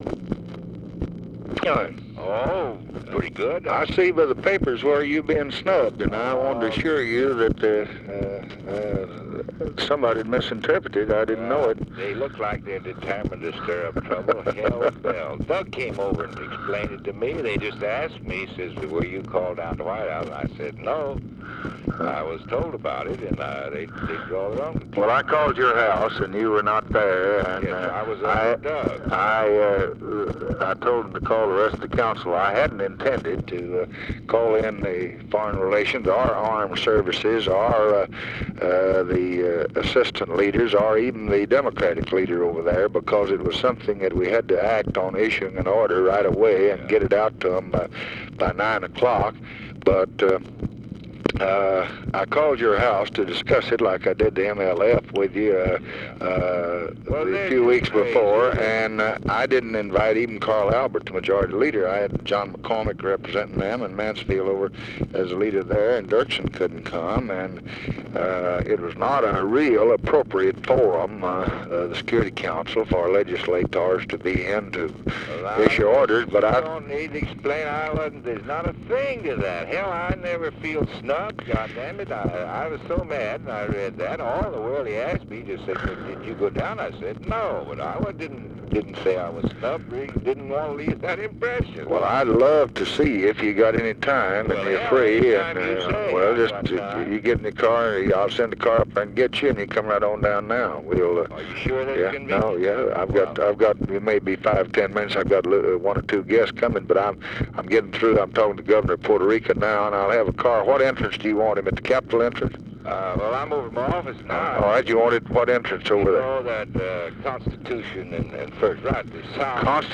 Conversation with WILLIAM FULBRIGHT, February 9, 1965
Secret White House Tapes